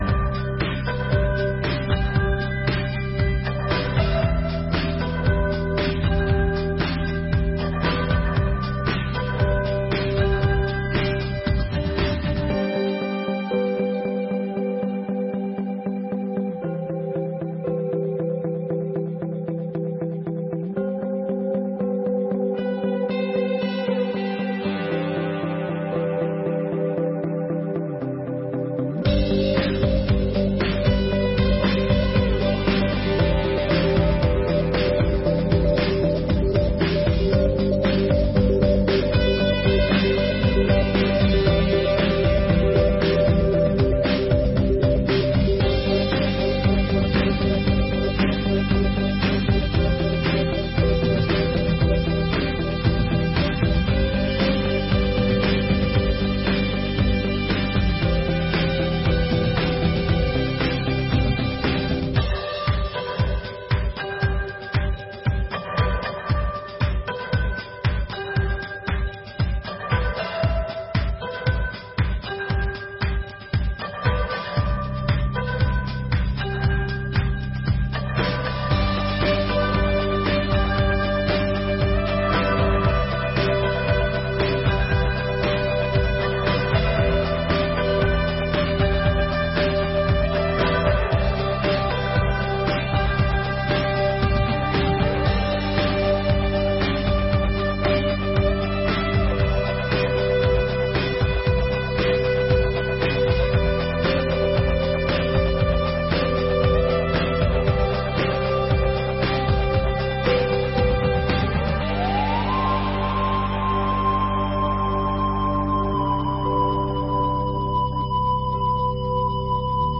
Sessões Solenes de 2024